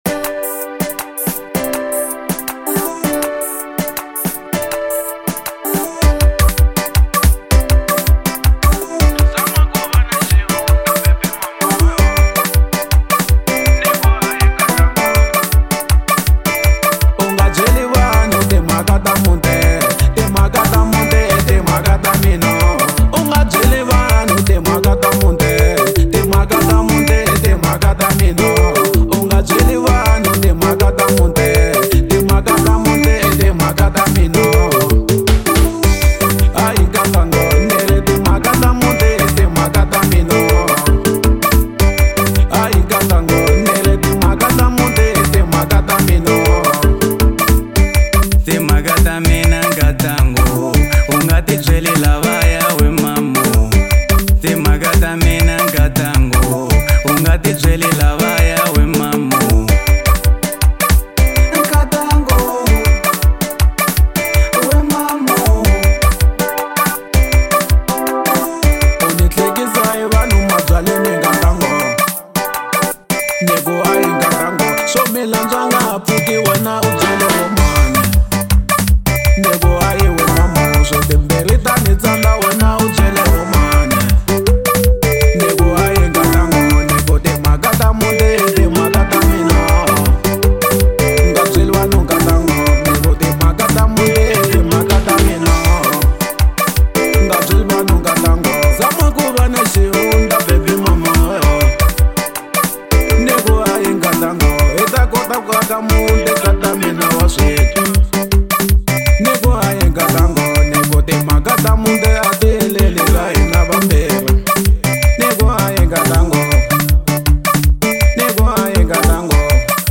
04:07 Genre : Xitsonga Size